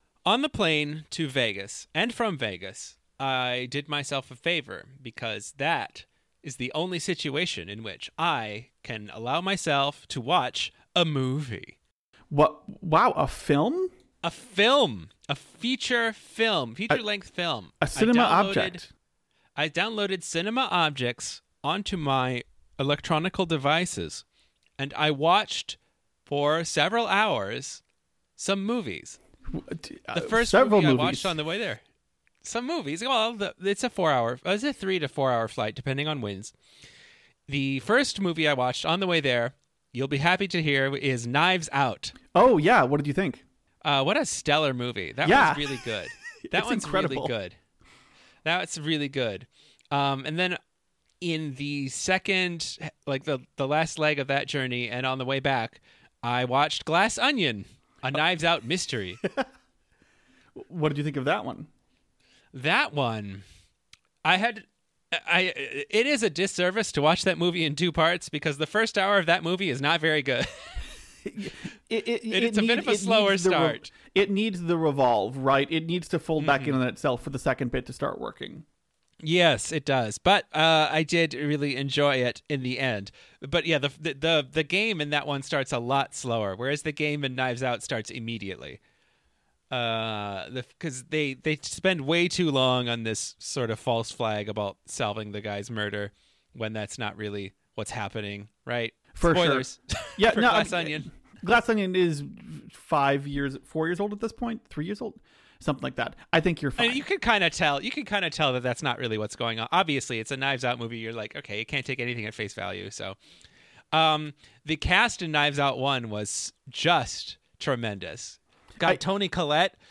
Join your Hosts of Light as they discuss a new gaming experience every week that won't take up all your free time to finish. Expect fun conversations about indie games, retro games, and even some board or card games! On top of that, there'll be fun quiz segments, maybe some trivia, or maybe a fun bit of comedy and improv!